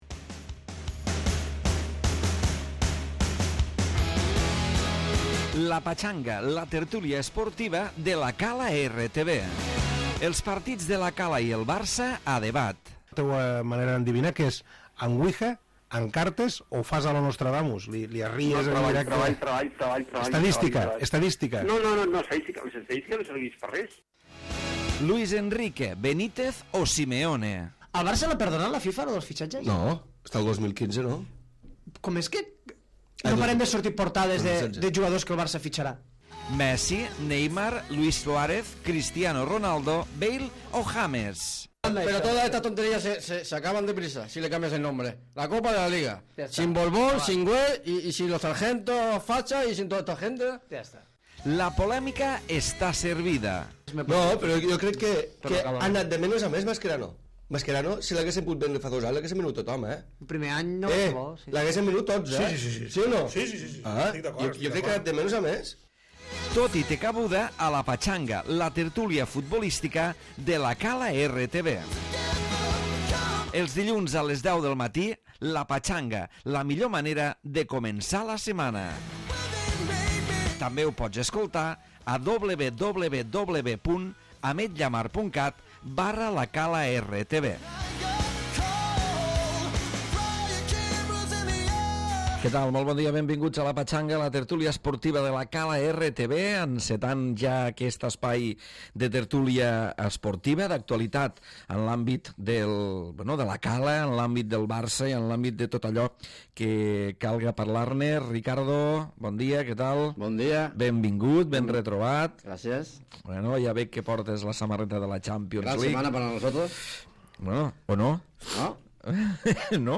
Tertúlia esportiva d'actualitat futbolística